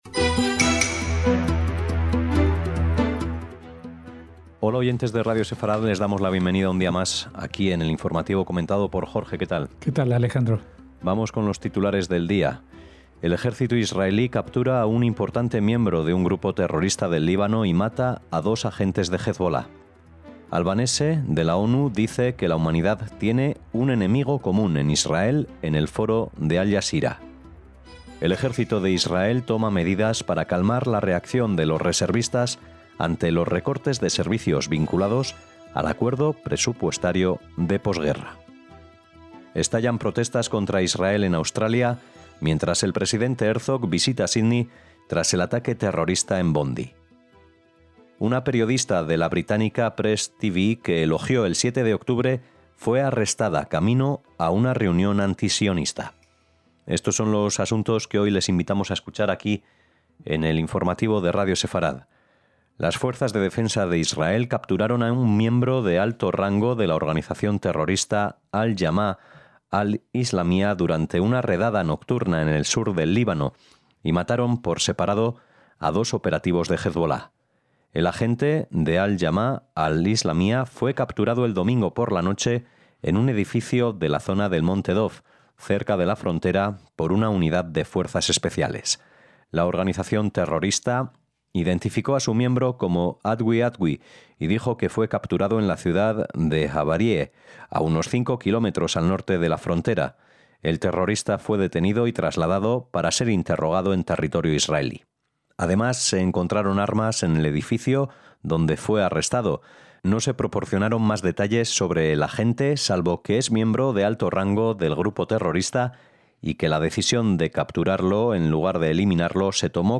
NOTICIAS – Titulares de hoy: El ejército israelí captura a un importante miembro de un grupo terrorista del Líbano y mata a dos agentes de Hezbolá.